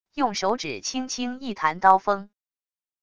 用手指轻轻一弹刀锋wav音频